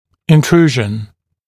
[ɪn’truːʒn][ин’тру:жн]интрузия, внедрение (зуба)